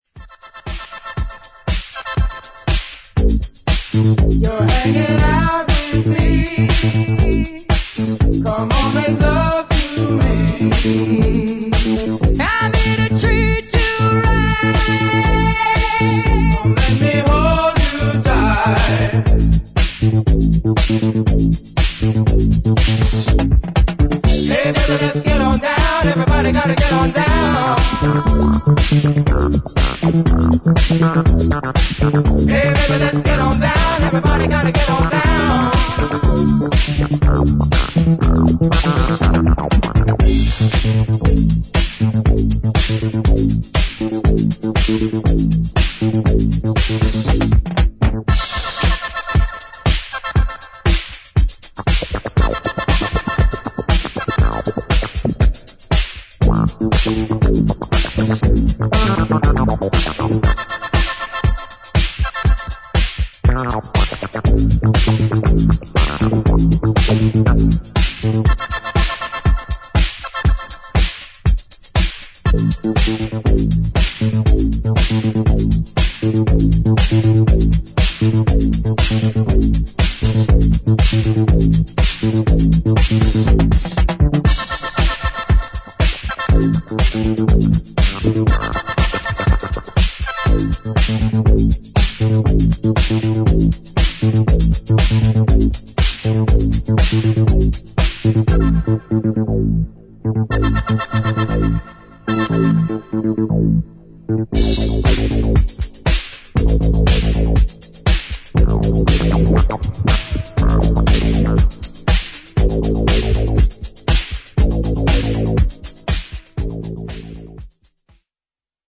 スネアの音色が特徴的なエレクトリック・ビートに、ファンクネス感溢れるウニョウニョとしたベースがグルーヴを紡ぎ
極上のハートウォーミング・ソウル・ナンバー